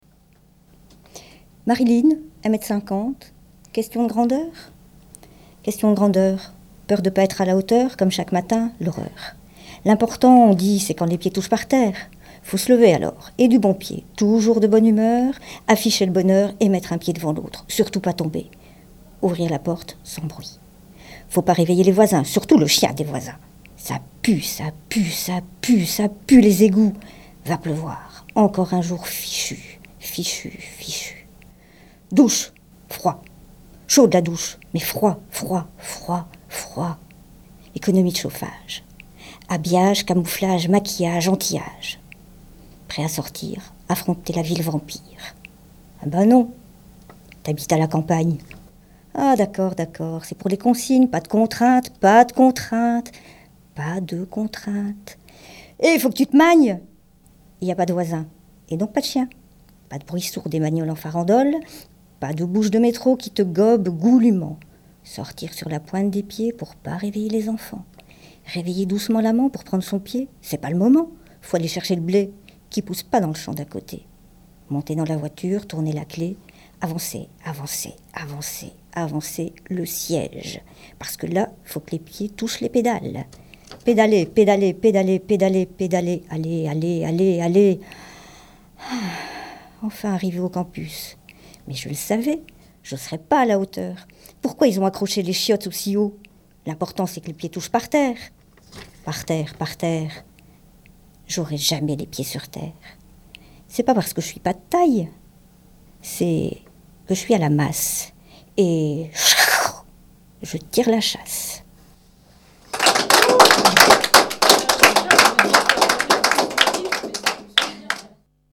ateliers slam , écriture et enregistrement de séquences
séquence slam 5